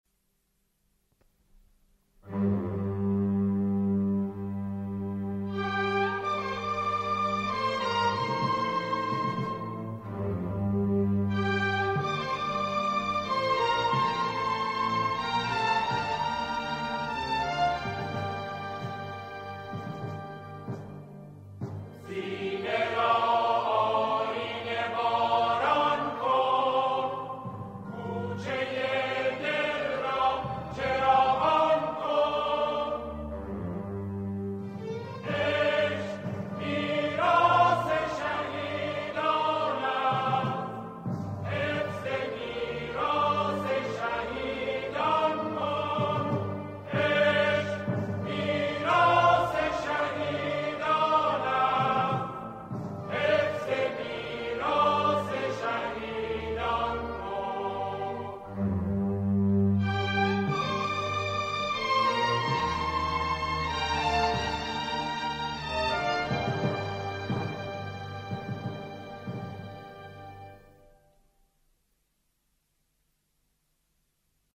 سرودهای شهدا